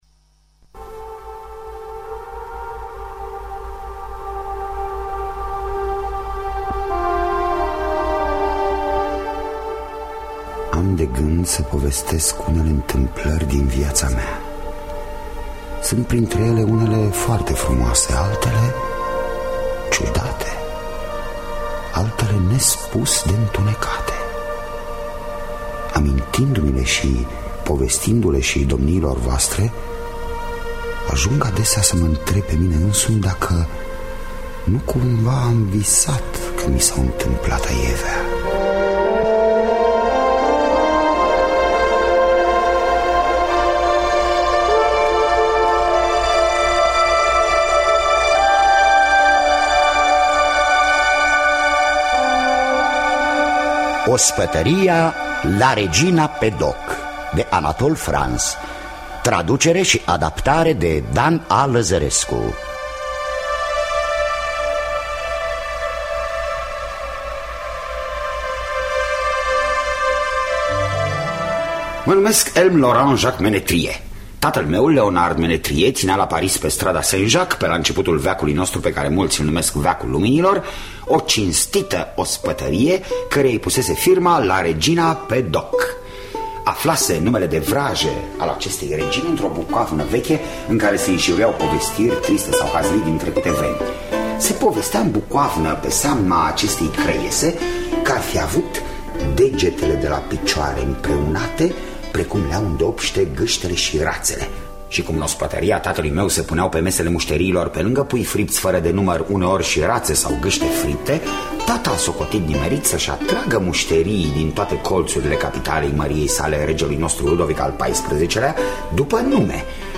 Ospătăria La regina Pedauque de Anatole France – Teatru Radiofonic Online